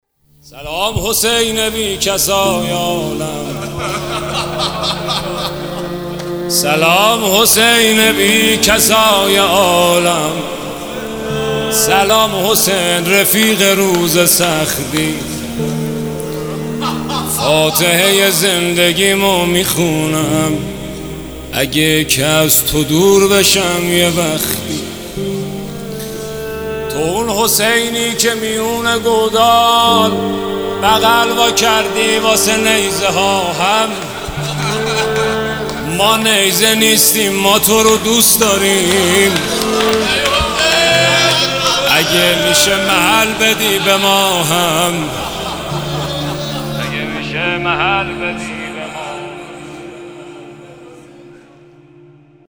مداحی
کیفیت بالا